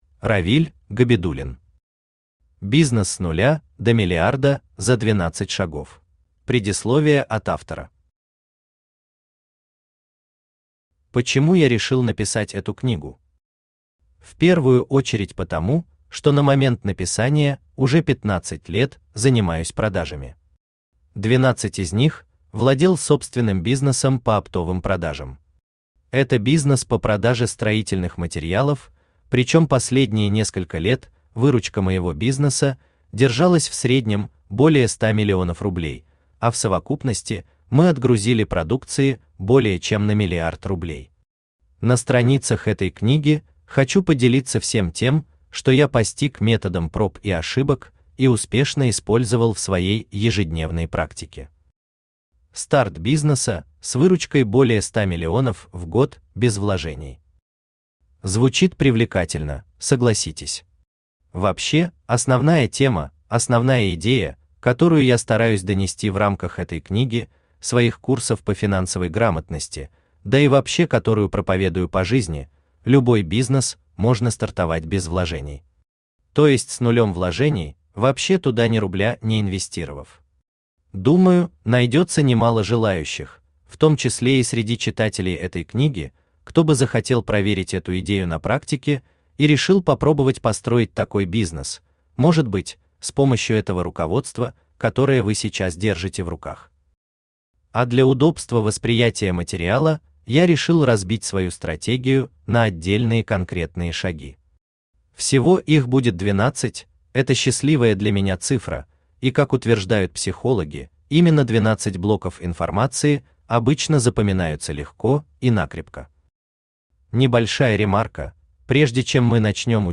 Аудиокнига Бизнес с нуля до миллиарда за 12 шагов | Библиотека аудиокниг
Aудиокнига Бизнес с нуля до миллиарда за 12 шагов Автор Равиль Габидуллин Читает аудиокнигу Авточтец ЛитРес.